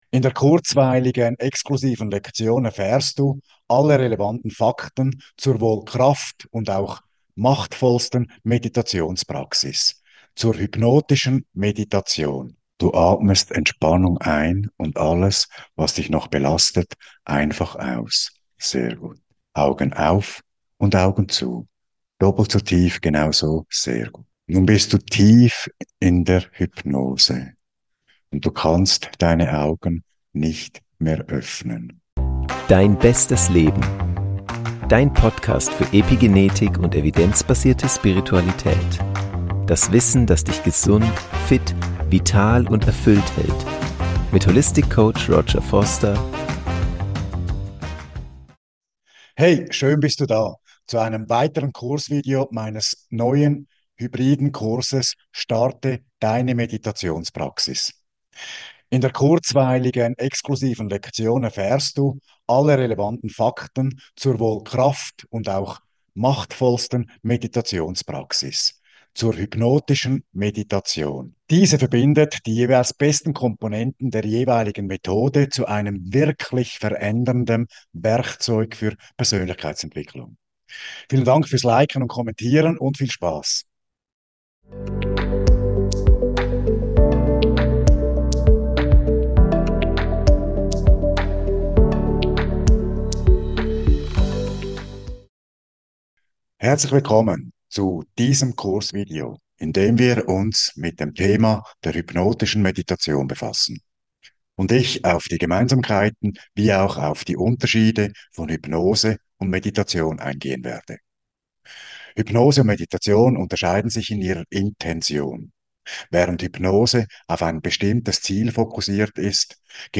Du lernst die Hintergründe von Hypnose und Meditation kennen und wie Du diese beiden Techniken einsetzen kannst - sowohl, um Deine körperliche und mentale Gesundheit zu verbessern als auch zur Persönlichkeitsentwicklung und dem Verwirklichen von Zielen durch die Arbeit im Unterbewusstsein. Das Kursvideo zeigt Dir nicht nur das theoretische Wissen auf, sondern lässt Dich mit zwei angeleiteten Hypnosen die Kraft der hypnotischen Meditation selbst erfahren.